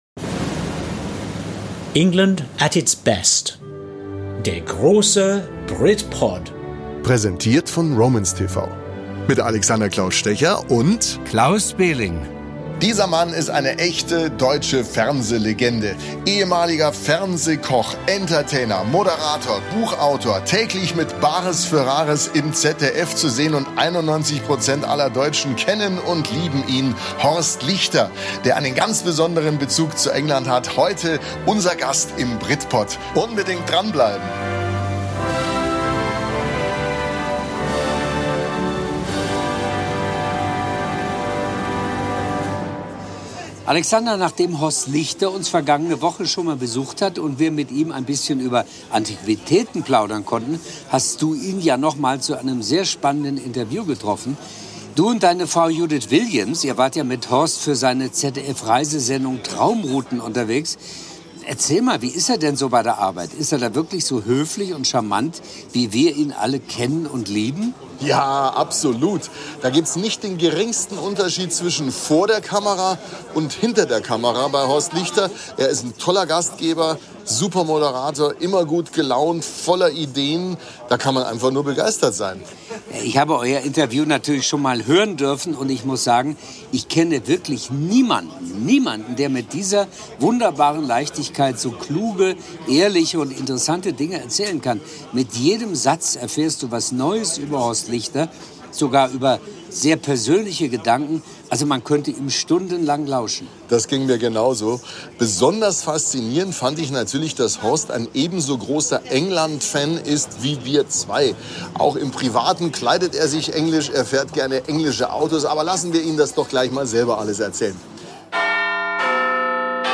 Beschreibung vor 2 Jahren Für Millionen Fans ist er eine Ikone des deutschen Fernsehens: Horst Lichter zu Gast im BRITPOD!